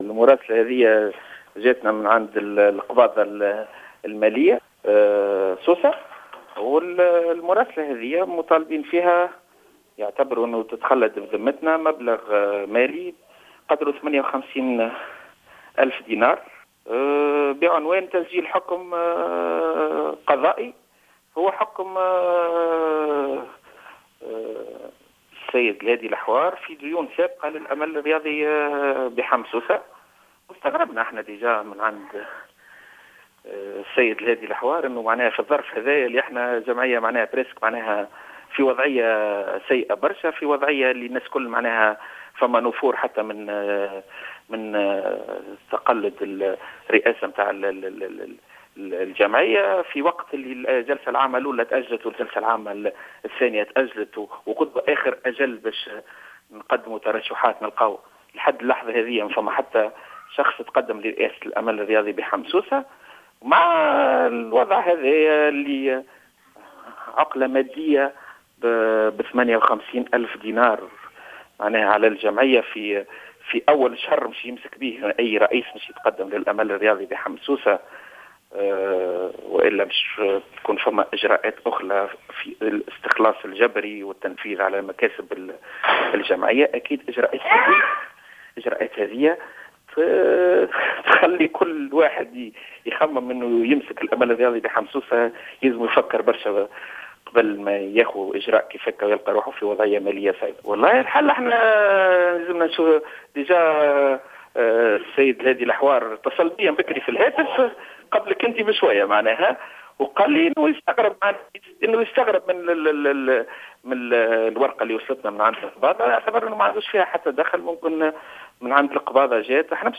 في تصريح لجوهرة اف ام